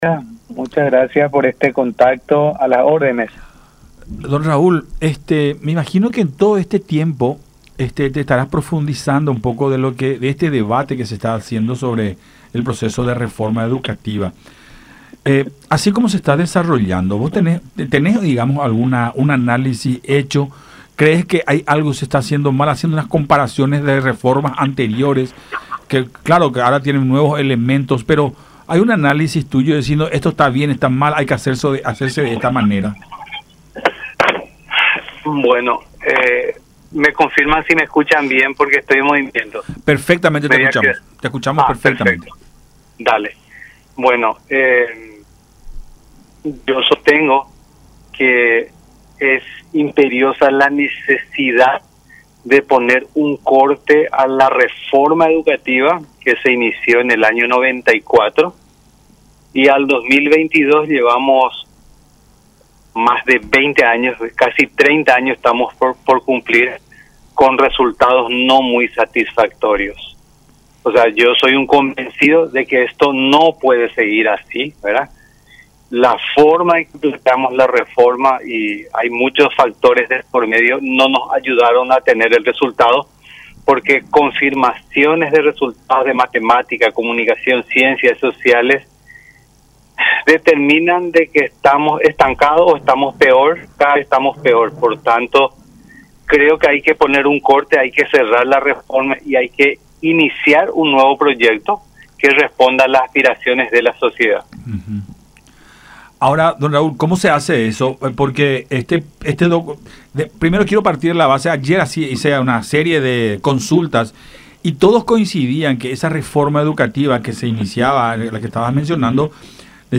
manifestó Aguilera en diálogo con Nuestra Mañana a través de Unión TV y radio La Unión.